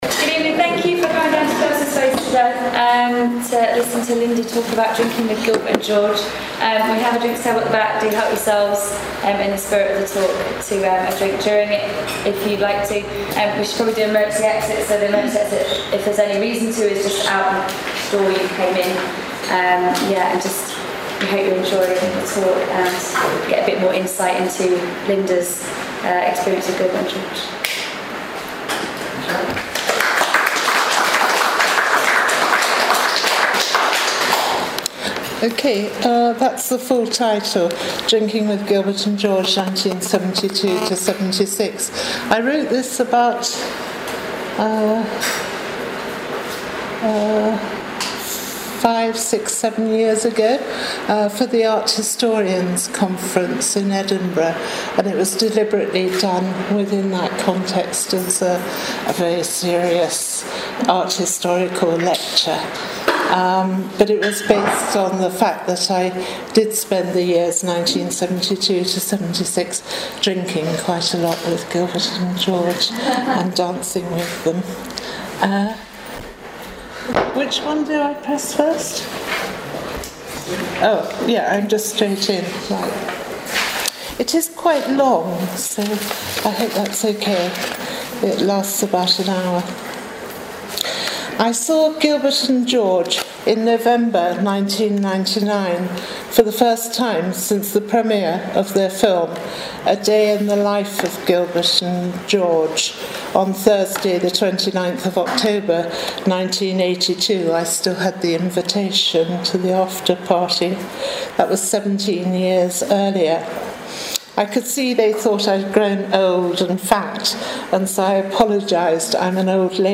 Talks
audience view